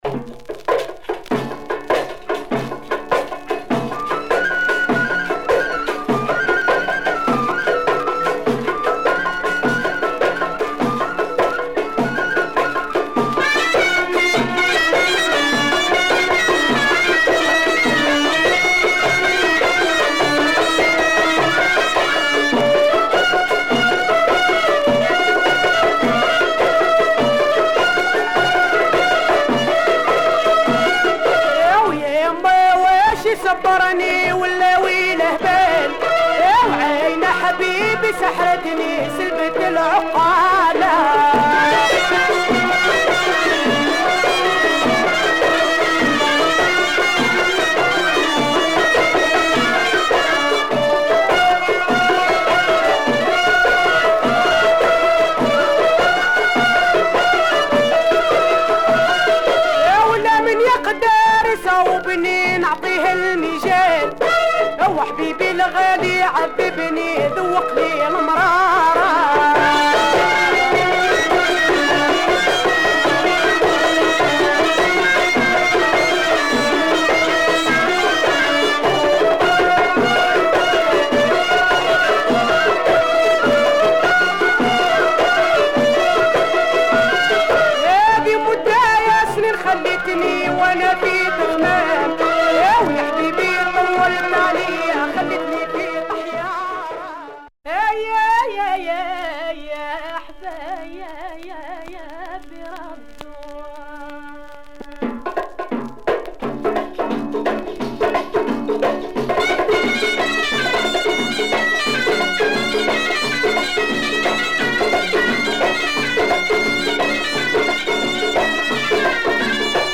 Very rare female raw chants from Algeria.